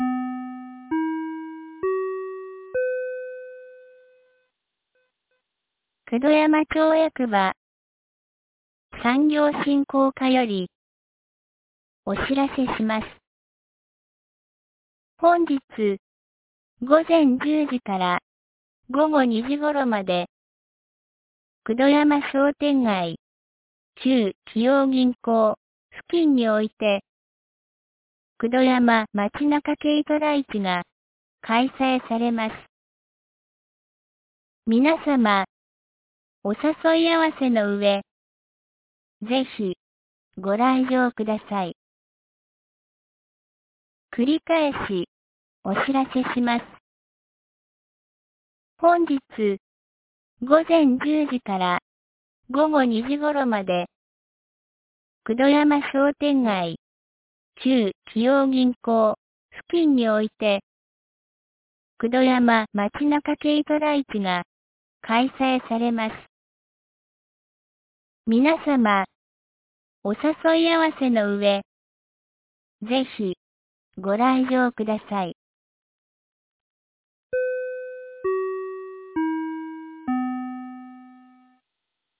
2025年04月06日 09時01分に、九度山町より全地区へ放送がありました。